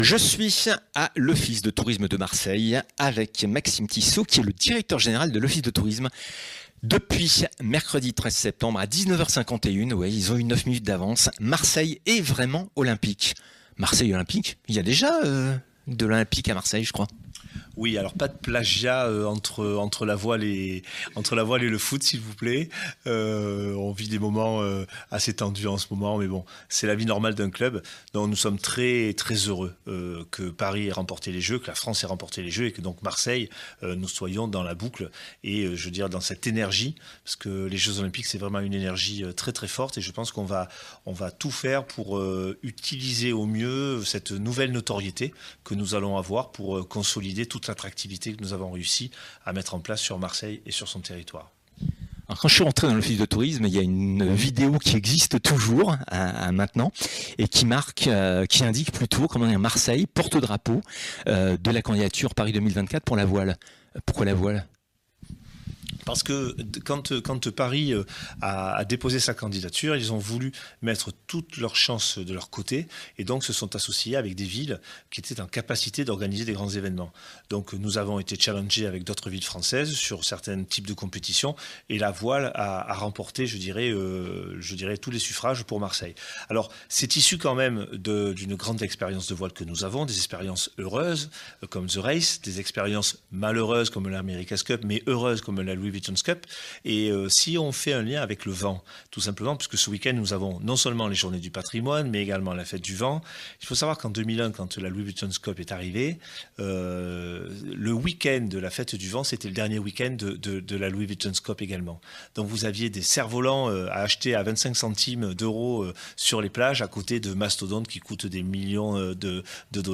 Interview realisee le lendemain de l'obtention des JO et JP Paris 2024 rendant Marseille Capitale Olympique pour les epreuves de Voile